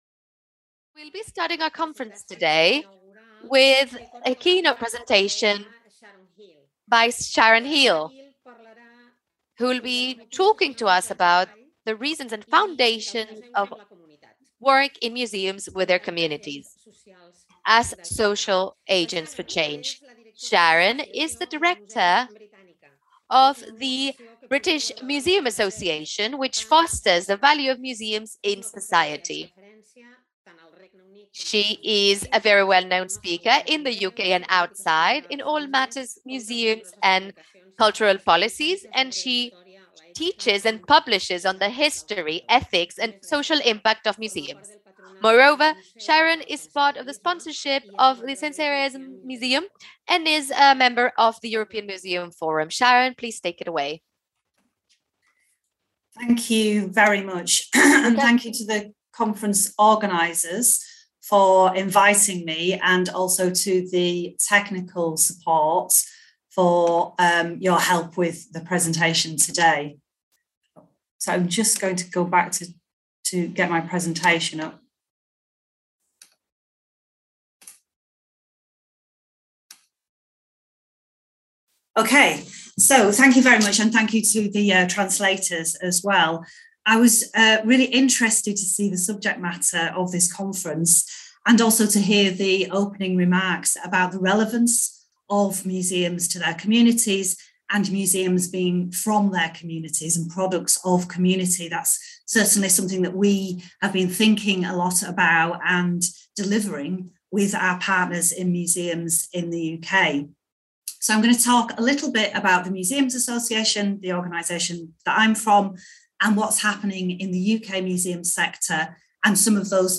Presentació inaugural